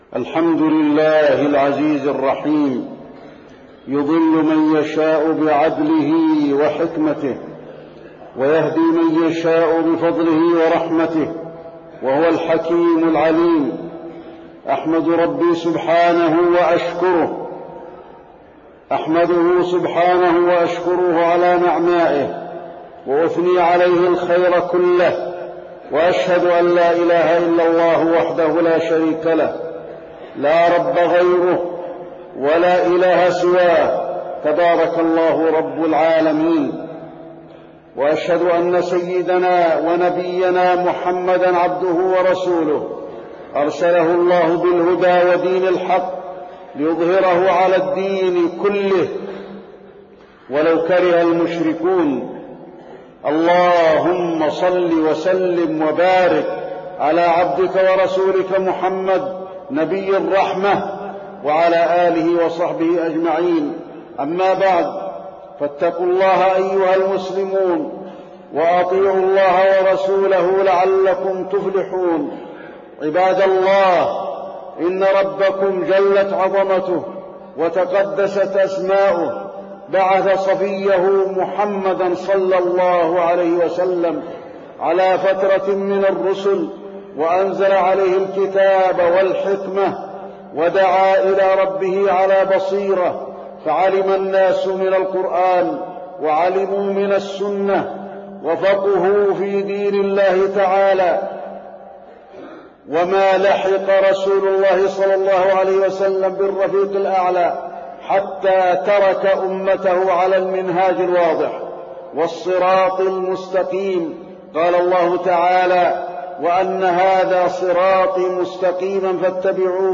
تاريخ النشر ١٨ ذو الحجة ١٤٢٨ هـ المكان: المسجد النبوي الشيخ: فضيلة الشيخ د. علي بن عبدالرحمن الحذيفي فضيلة الشيخ د. علي بن عبدالرحمن الحذيفي المذاهب وسنة الخلاف